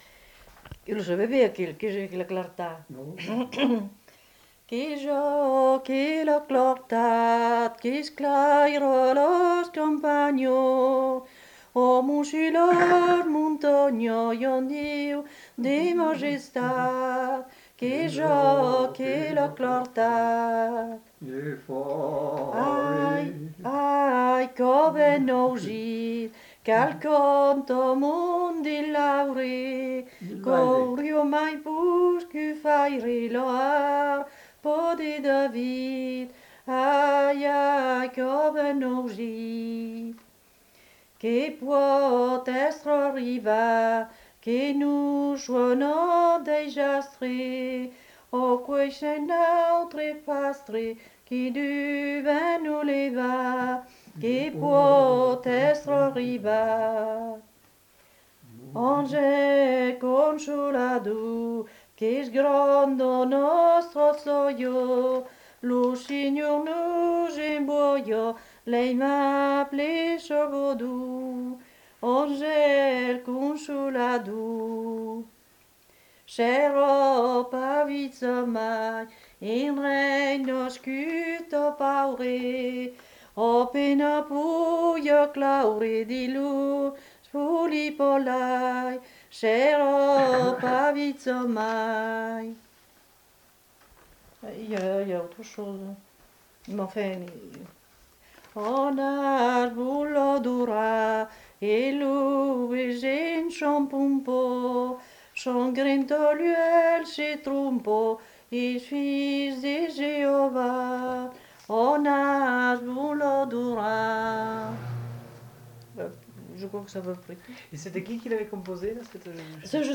Lieu : Lacroix-Barrez
Genre : chant
Effectif : 1
Type de voix : voix de femme
Production du son : chanté
Classification : noël